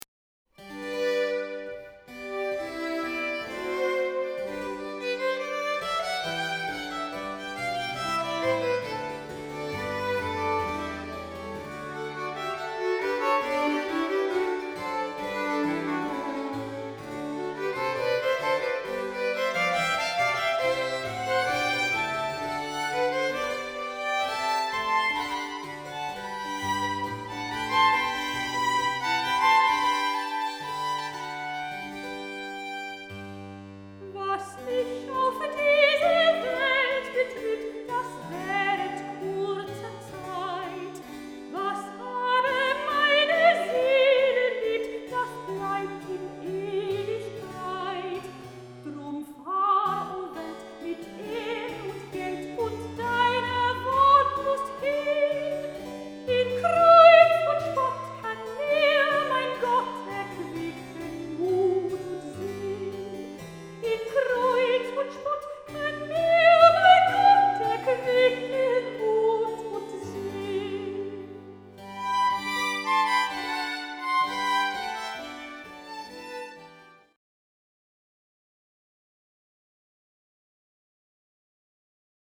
2 viulua ja bc